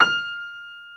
55p-pno34-F5.wav